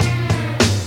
Vln Gls 374 2 Snr-F3.wav